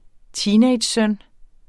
Udtale [ ˈtiːnεjdɕ- ]